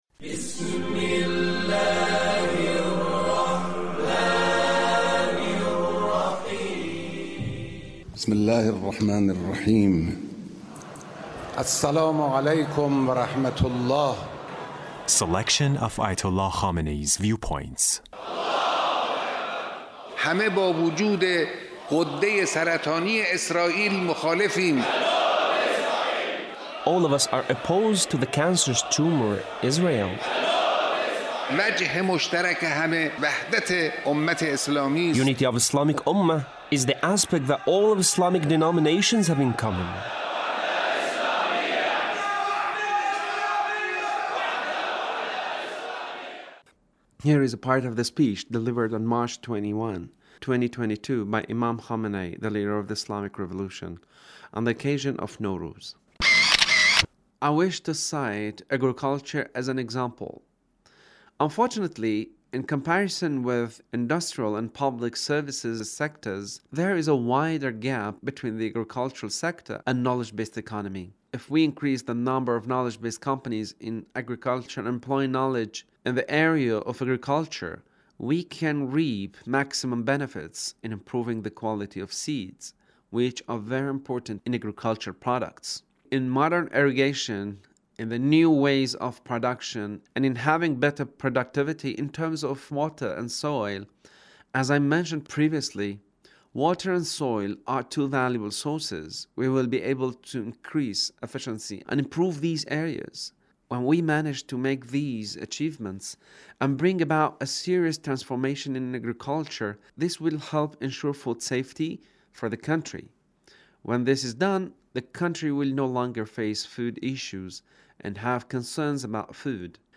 Leader's Speech on Knowledge Based Economy